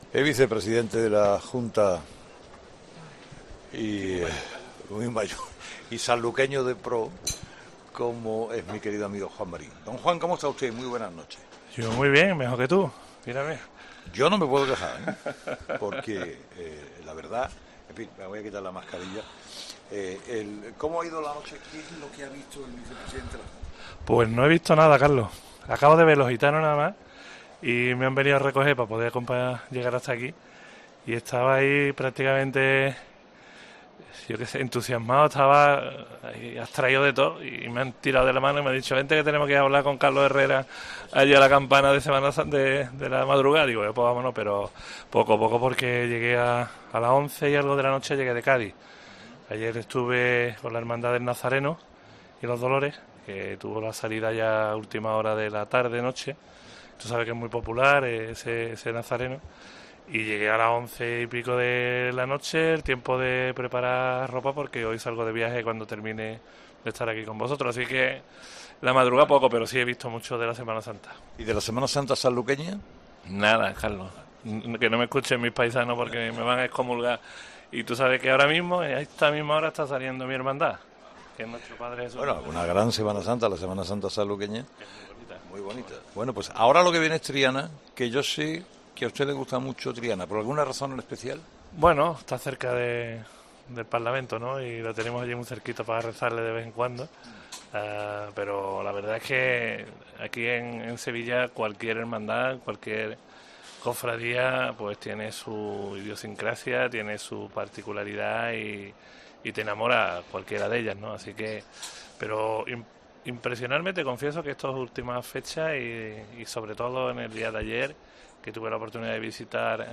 El vicepresidente de la Junta de Andalucía ha compartido con Carlos Herrera unos minutos durante 'La Madrugá' de Sevilla